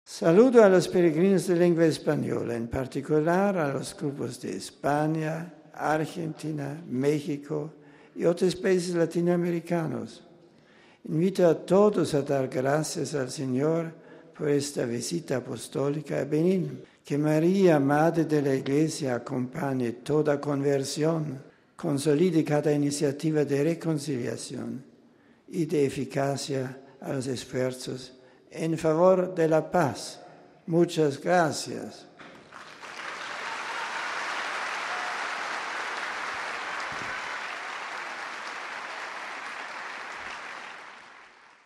Al saludar a los fieles procedentes de América Latina y de España, el Papa les agradeció su presencia con las siguientes palabras: RealAudio